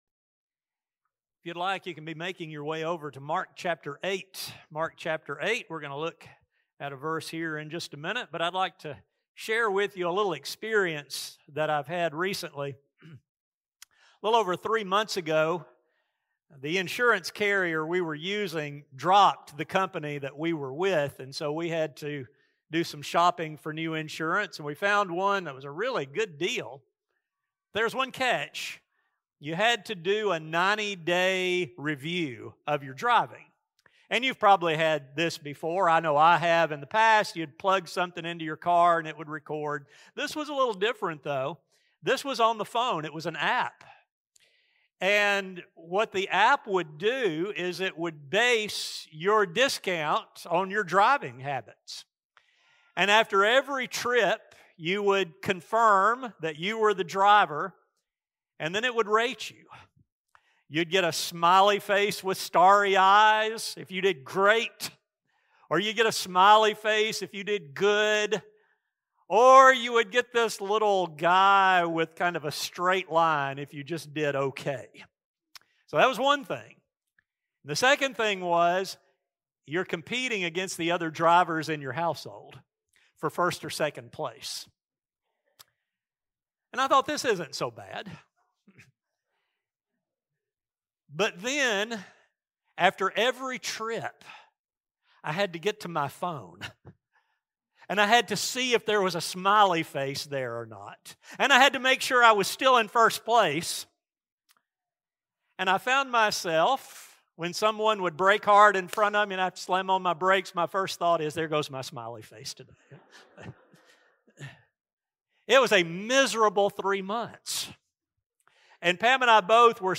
How, though, does one actually follow Jesus? This study will focus on what discipleship entails as well as those things that can derail real discipleship. A sermon recording